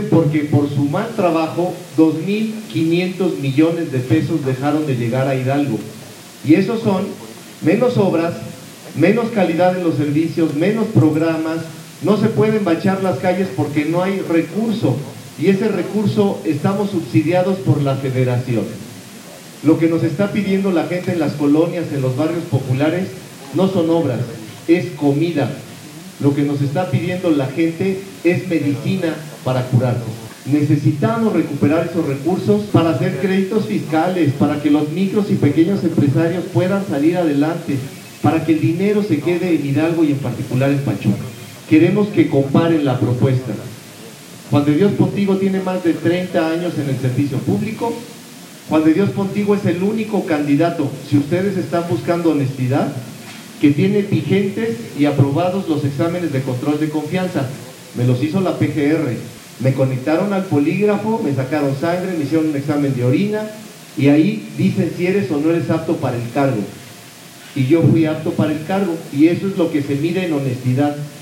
Juan de Dios Pontigo Loyola candidato por la coalición Va por Hidalgo por el distrito XIII por Pachuca, visitó la empresa “Ten Pac”, donde fue escuchado atentamente por el personal que labora en esta compañía, la cual se dedica a la elaboración de zapatos industriales.
En su mensaje a los trabajadores de la empresa, Pontigo Loyola les refirió que él entiende las necesidades de los pachuqueños, puesto que es claro el mensaje que recibe de parte de la sociedad civil y empresarios en sus recorridos, tener liquidez para poder adquirir los insumos básicos de manutención, para poder mantener la economía de sus negocios y con esto poder seguir empleando a personas.